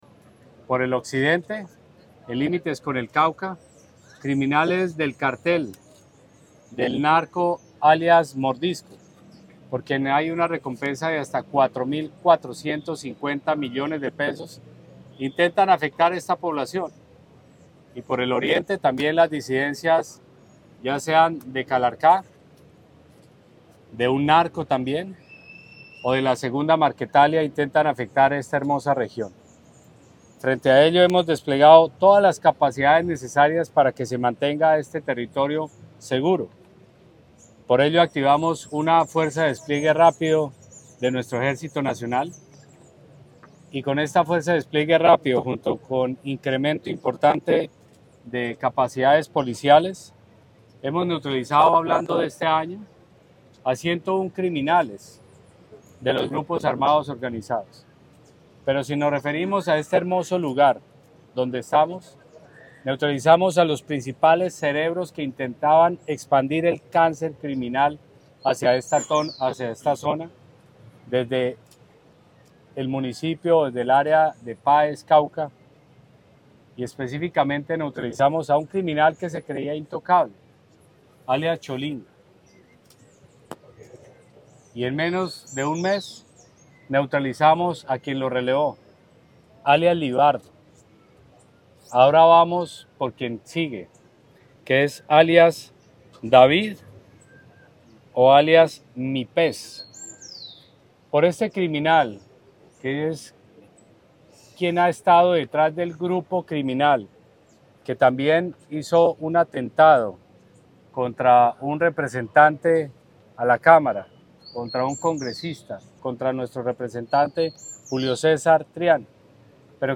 En el Consejo de Seguridad desarrollado en el municipio de La Plata (Huila), el Gobierno Nacional, en articulación con la Gobernación del Huila, anunció el pago de millonarias recompensas por información que permita desarticular estructuras criminales, frenar las economías ilícitas y capturar a los responsables de hechos violentos que afectan la tranquilidad de los habitantes del occidente del departamento.
1.-Pedro-Arnulfo-Sanchez-.-Ministro-de-Defensa.mp3